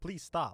Voice Lines / Dismissive
please stop.wav